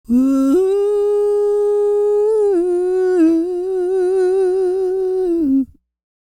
E-CROON P329.wav